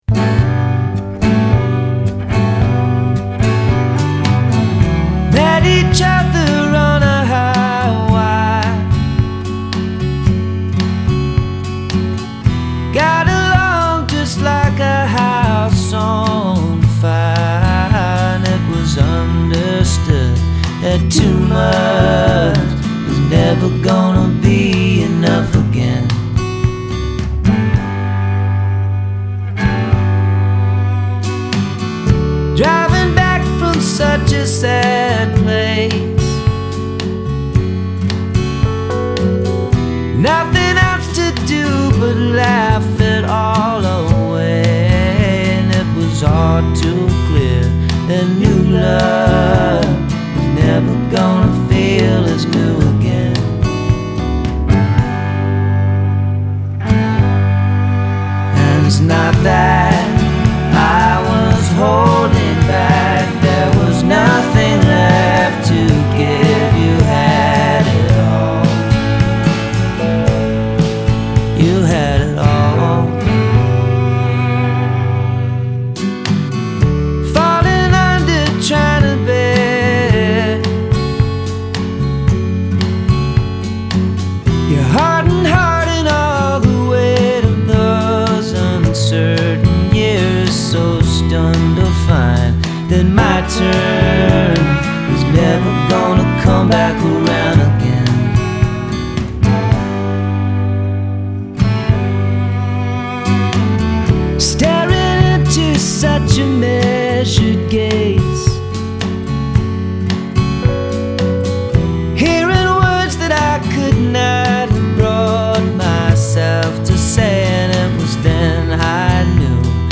guitar
drums
cello). Mixed in Hilversum, the Netherlands